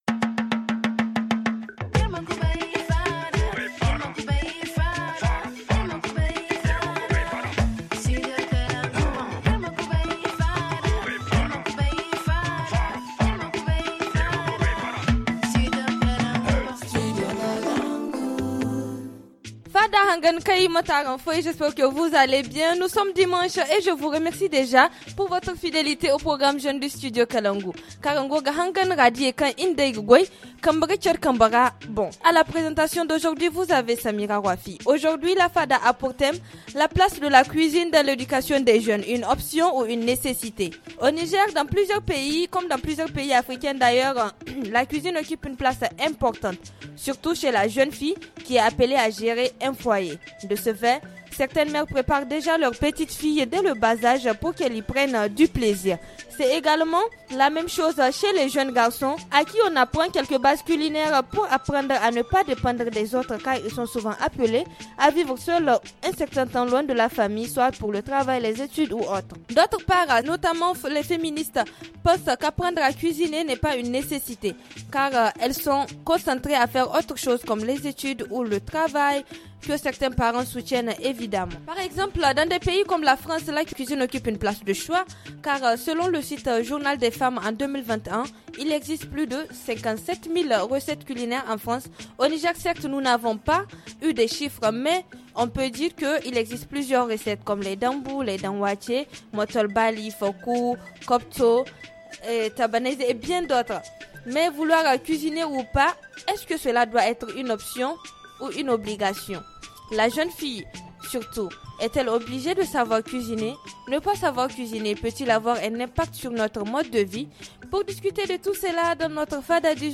Ne pas savoir faire la cuisine, est-ce que cela pourrait avoir un impact sur la vie conjugale de la femme ? Pour trouver réponses à nos interrogations, nous avons convié au studio kalangou des jeunes: